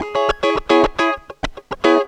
GTR 95 C#M.wav